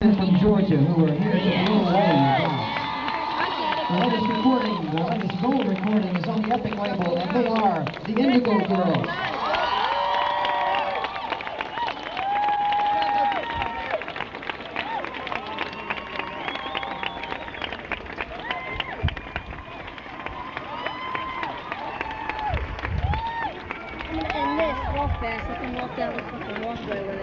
lifeblood: bootlegs: 1990-08-11: newport folk festival - newport, rhode island
(acoustic duo show)